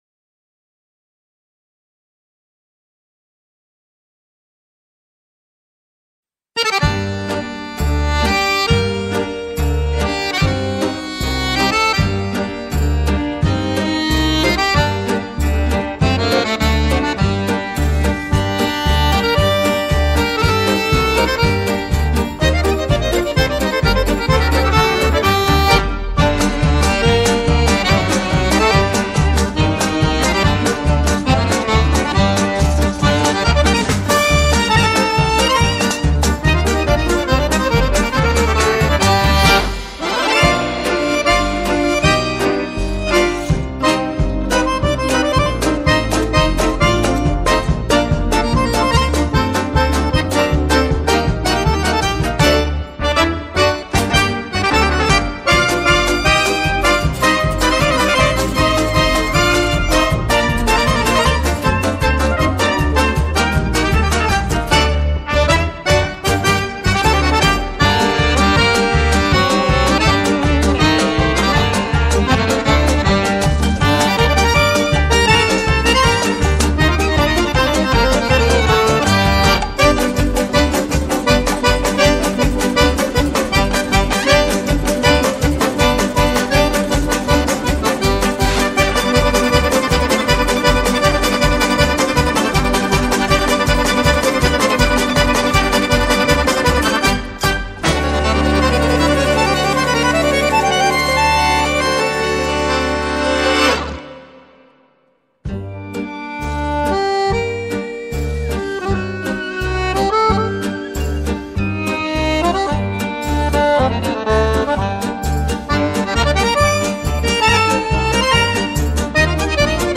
in G Minor